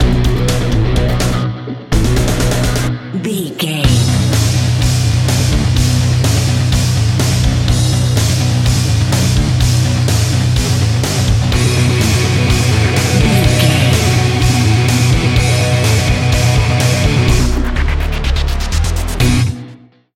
Epic / Action
Fast paced
In-crescendo
Aeolian/Minor
Fast
distorted
Pulsating